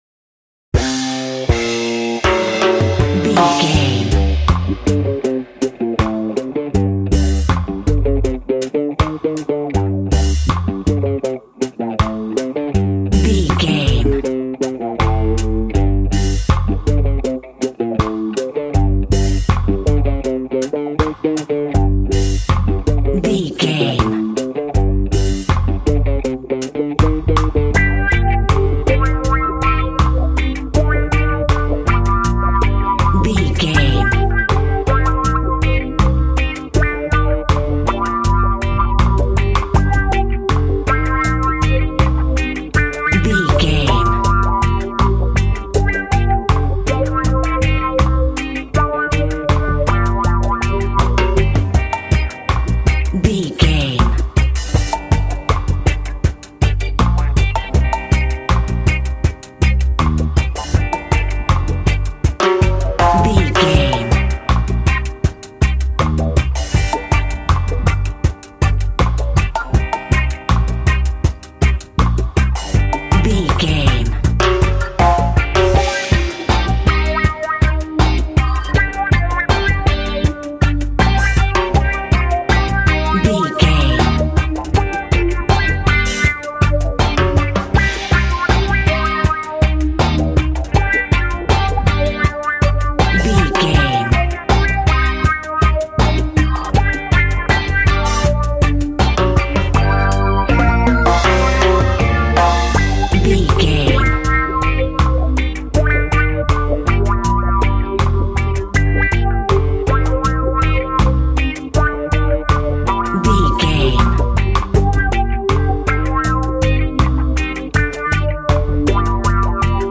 Aeolian/Minor
cheerful/happy
mellow
drums
electric guitar
percussion
horns
electric organ